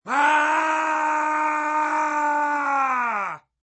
Descarga de Sonidos mp3 Gratis: grito 9.